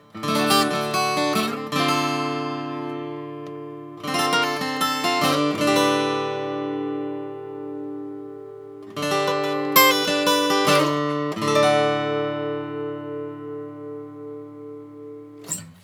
This guitar has a very tight, very articulate tone that is best described as focused.
D-Shape Chords
Since this is an acoustic guitar without electronics, I recorded it with my trusty Olympus LS10 recorder. I put the recorder on my desk (on a foam pad) and positioned the guitar with the sound hole about 18″ from the mics.